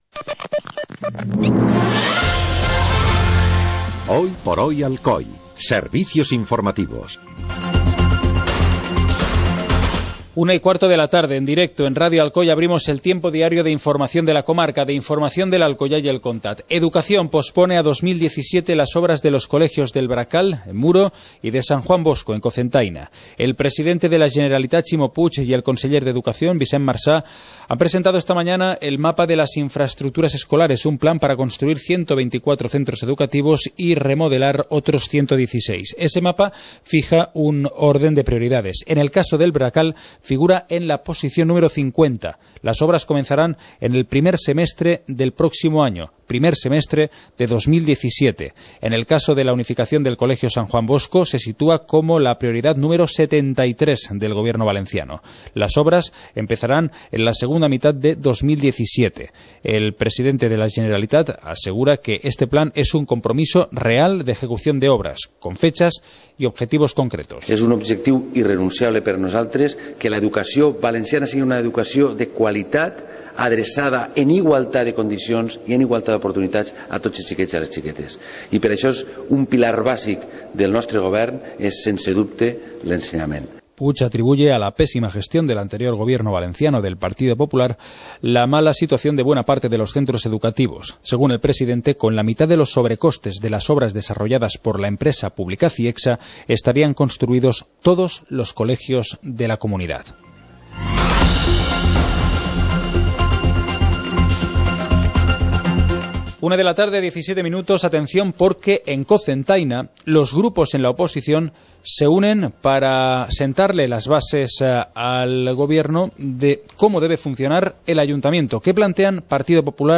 Informativo comarcal - martes, 26 de enero de 2016